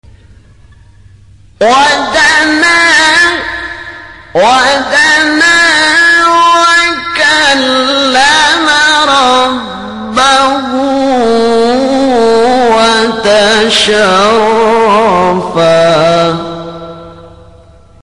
حجاز کار(جواب)4.mp3
حجاز-کار-جواب-4.mp3